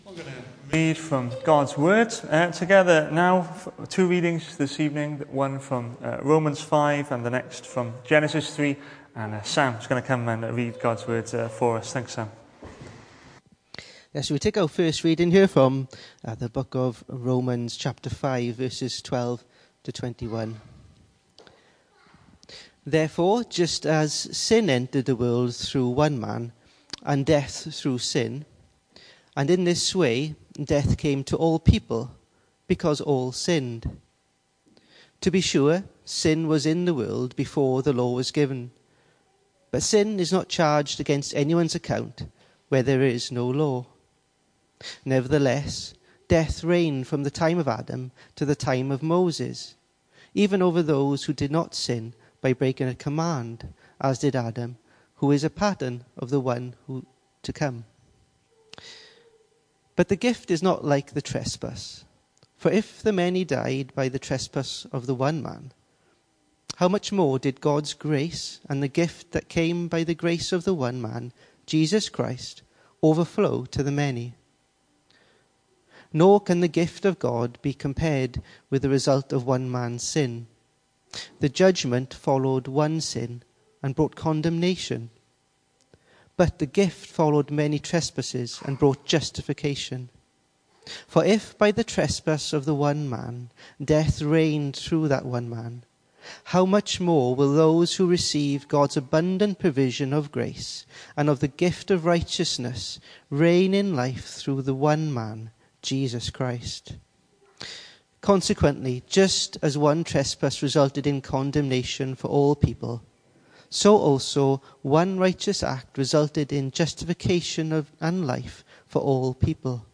Hello and welcome to Bethel Evangelical Church in Gorseinon and thank you for checking out this weeks sermon recordings.
The 27th of October saw us hold our evening service from the building, with a livestream available via Facebook.